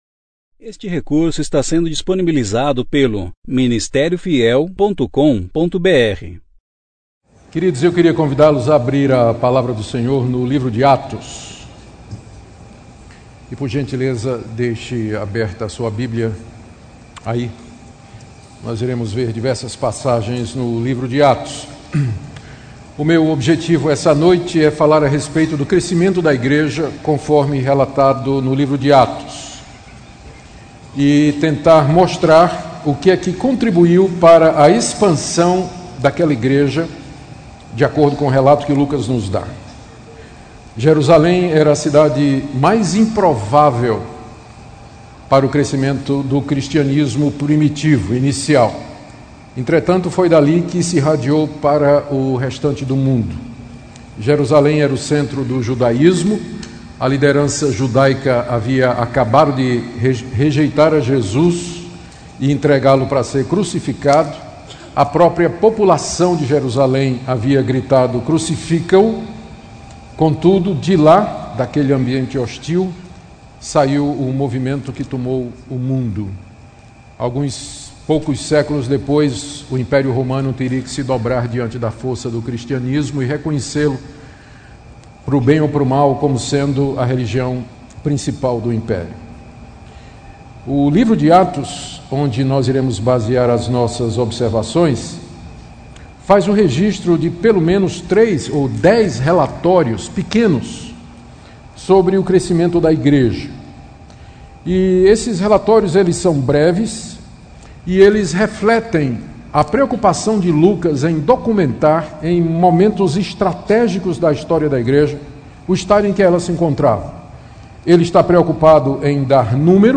Conferência: 32ª Conferência Fiel para Pastores e Líderes Tema
2016 Mensagem